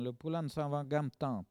Langue Maraîchin
Catégorie Locution